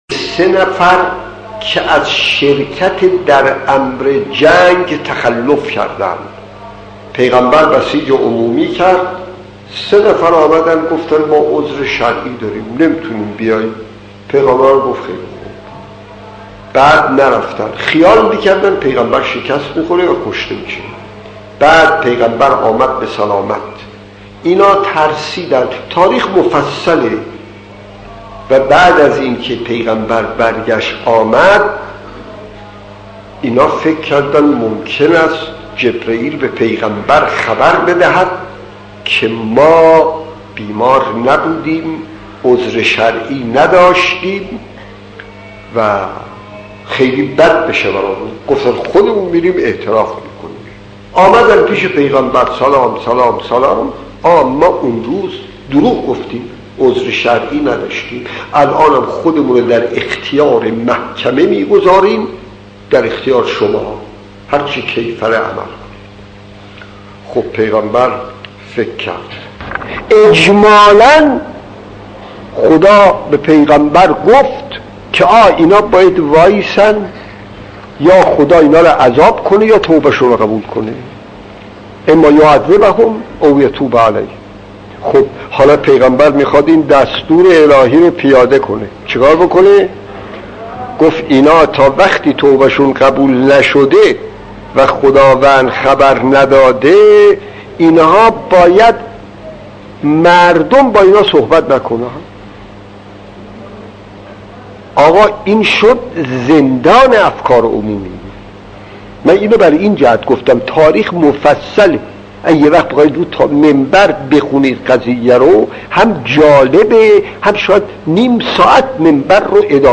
داستان 46 : افرادی که به جبهه جنگ نرفتند خطیب: استاد فلسفی مدت زمان: 00:02:46